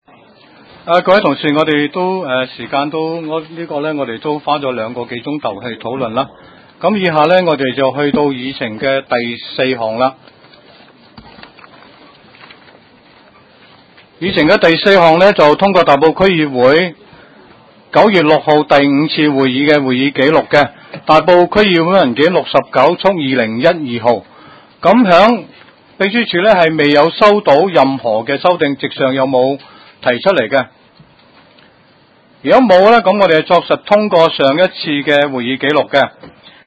区议会大会的录音记录